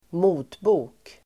Ladda ner uttalet
Uttal: [²m'o:tbo:k]